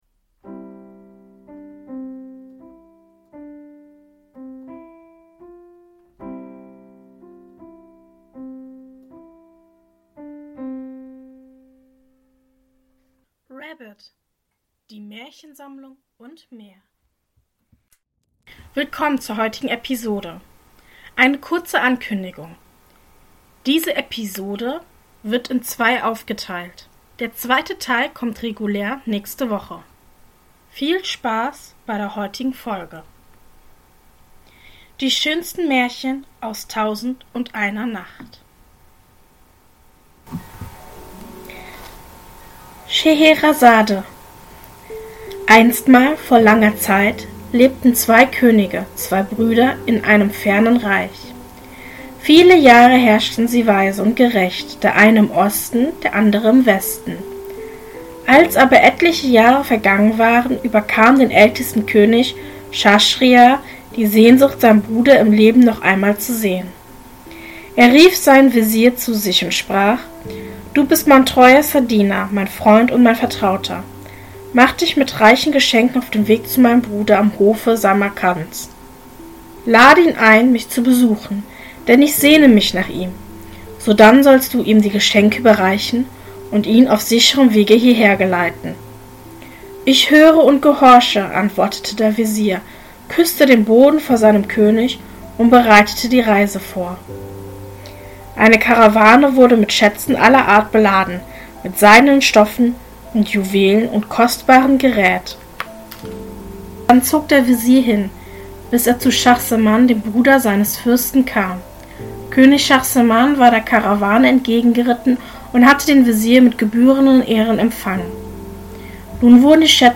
In diesem Podcast erzähle ich Euch verschiedene Märchen und möchte Euch einladen zu träumen und die Zeit gemeinsam zu genießen. Die Märchen werden aus aller Welt sein und sollen Euch verleiten, dem Alltag etwas zu entfliehen.